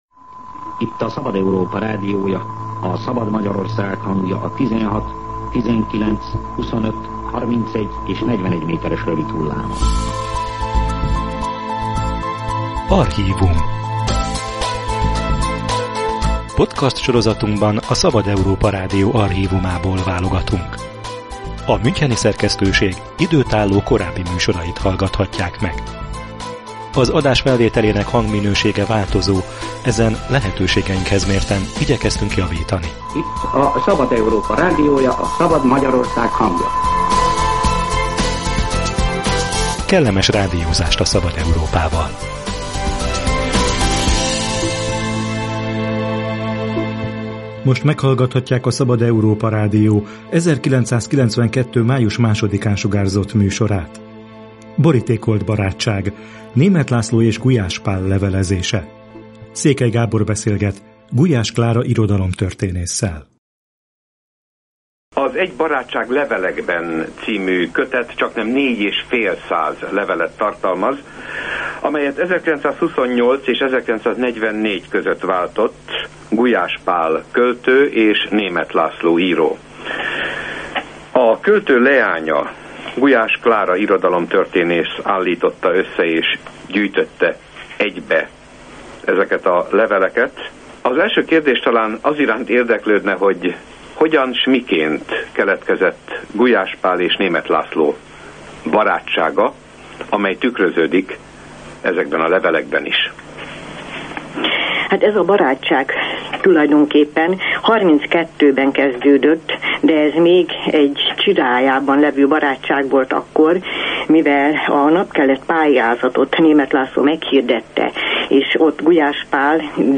Nyomon követhető benne a szélsőségek térnyerése és az erre adott értelmiségi válaszok, illetve azok lehetetlensége. A Szabad Európa Rádió interjúja